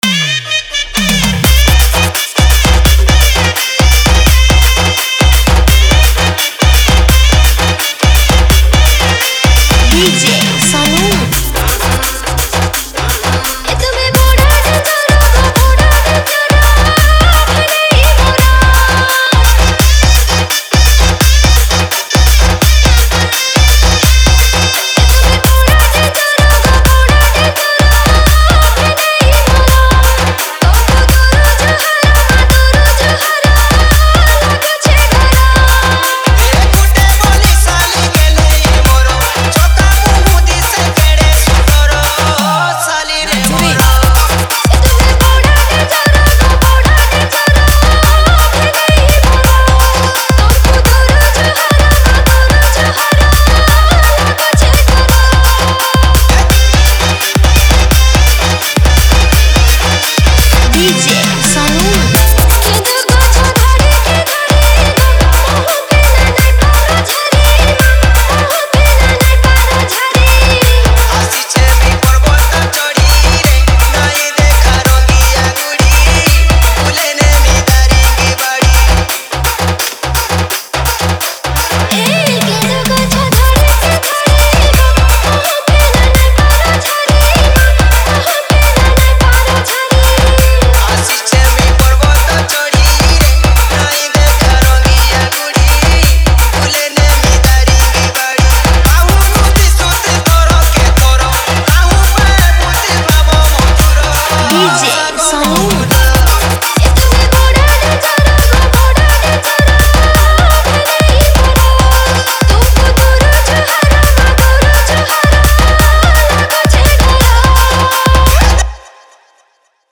TAPORI DANCE MIX